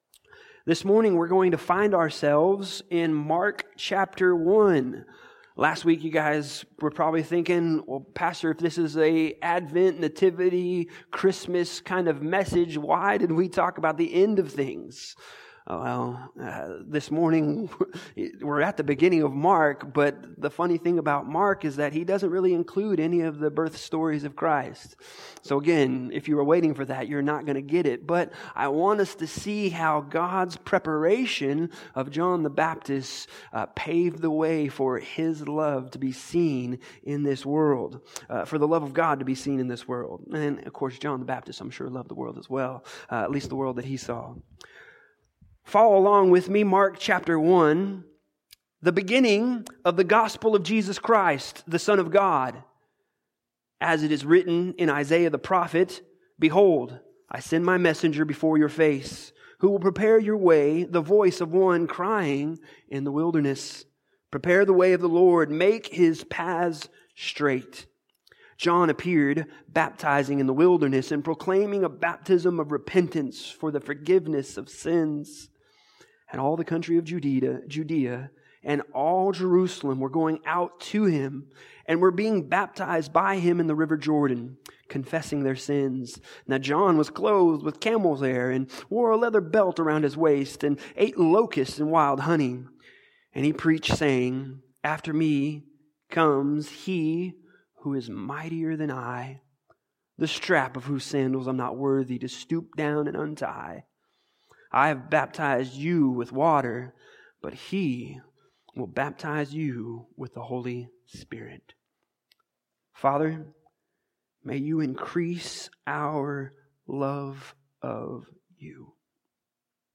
This sermon continues our series entitled “Advent 2020: Merry & Bright” where we look celebratory at the coming of Christ to earth to take away our sins. The season of Advent should also inspire hope as we expectantly wait for the Second Coming of Christ when His final victory over death and sin will be won.